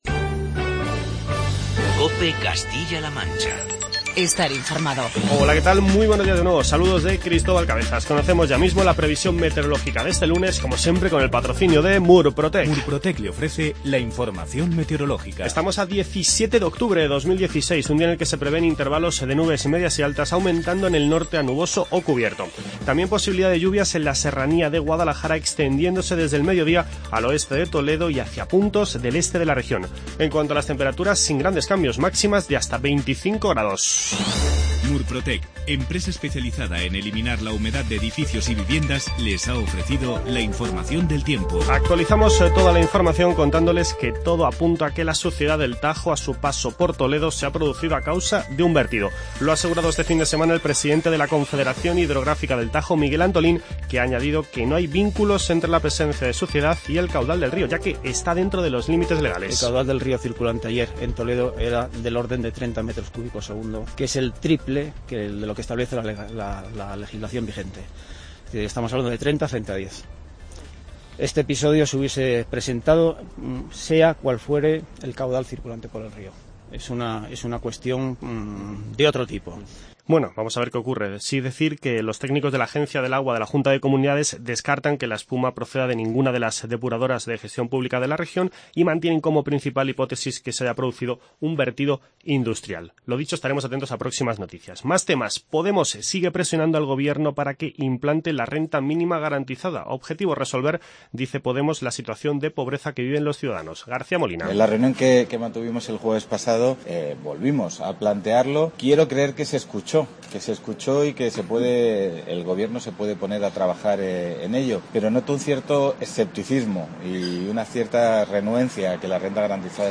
Informativo COPE Castilla-La Manca